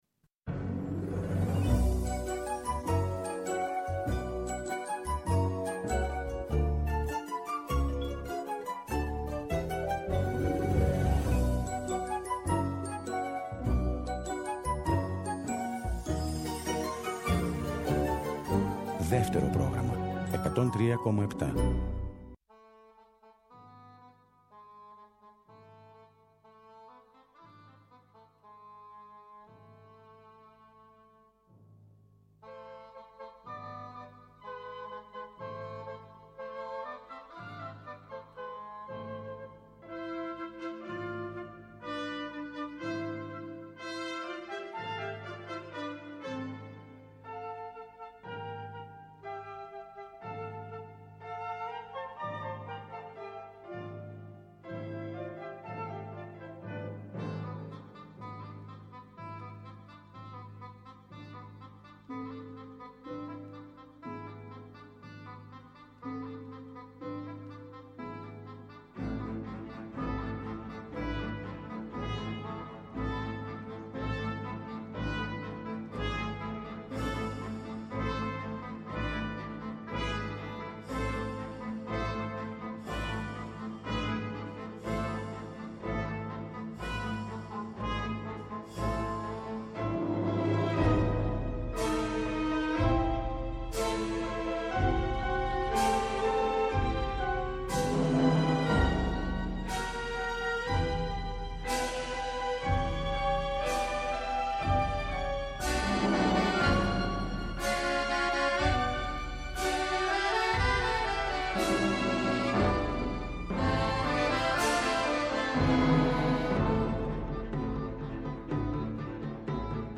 Δεκατέσσερα τραγούδια δεκατέσσερις διαφορετικές εικόνες.